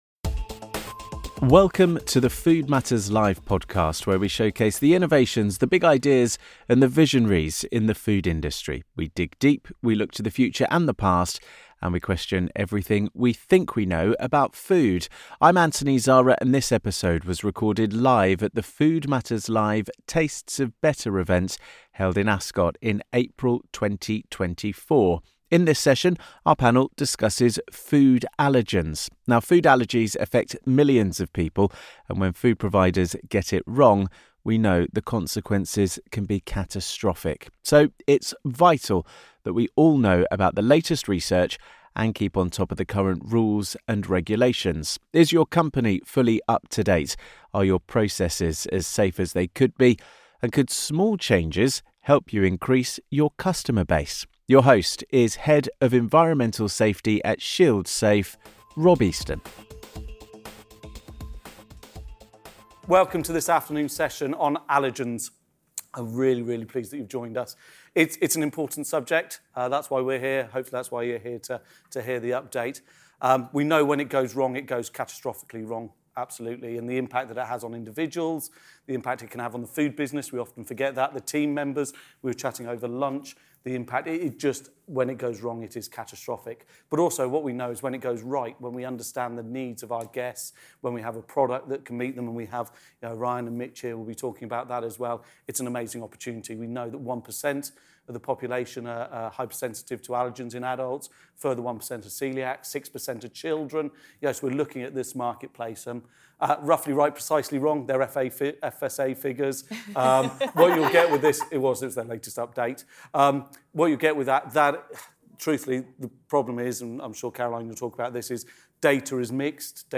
In this episode of the podcast, recorded live at Food Matters Live held in Ascot in April 2024, we look at the risks, the rules, and the opportunities (yes, there are some) when it comes to the relationship between allergens and food.
Our expert panel offer their experience and insights in this vital area, and offer answer to some of those crucial questions.